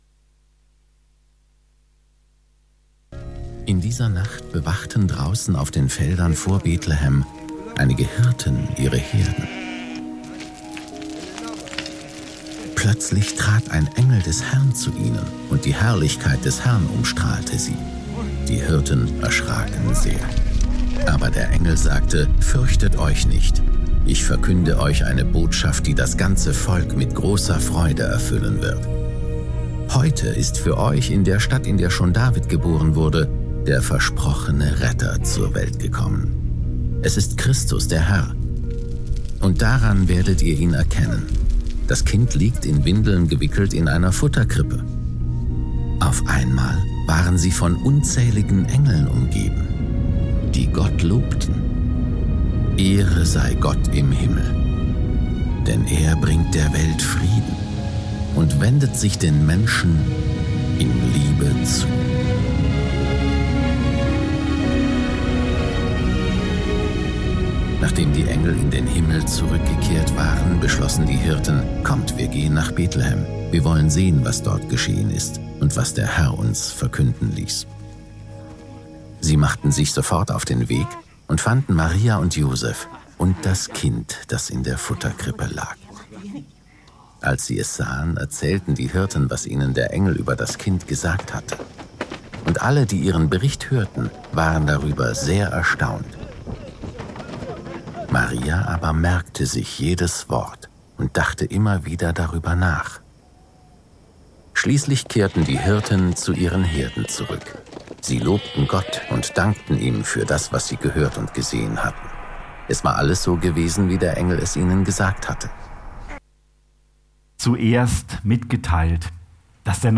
Predigt vom 24.